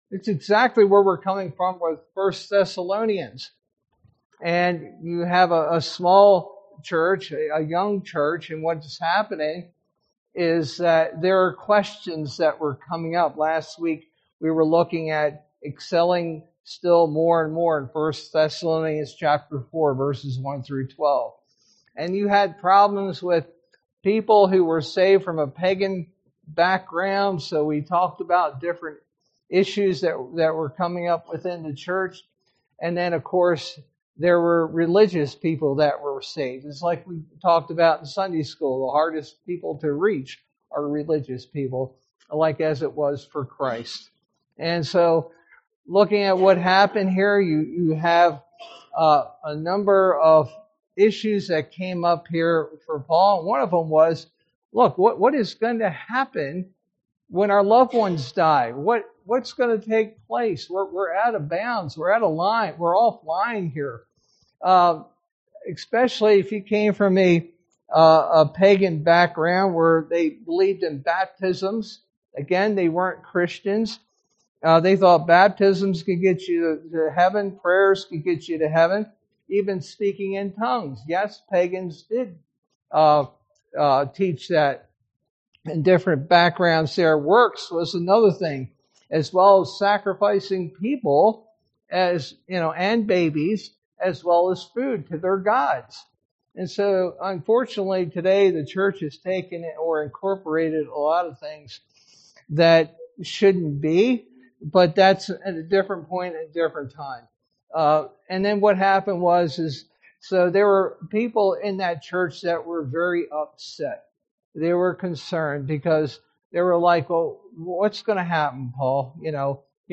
sermon verse: 1 Thessalonians 4:13-18